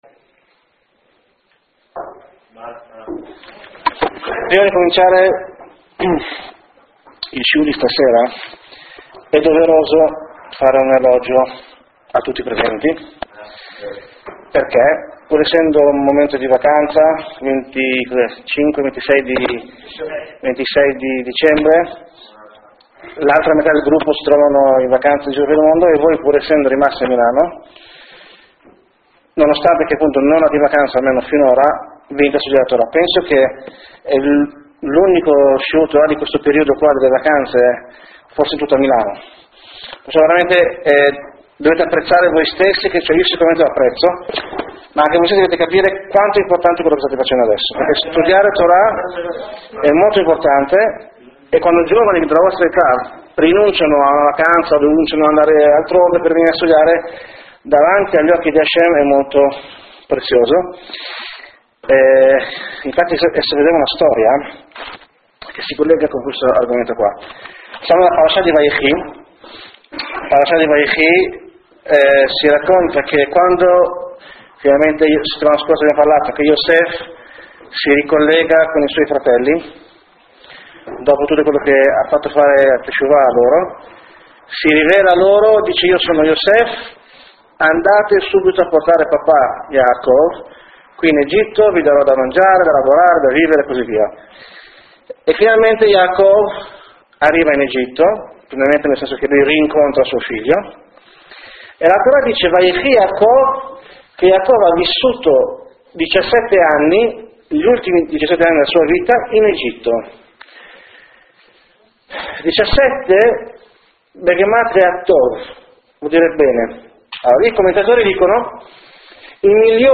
Alcuni punti della lezione: 1.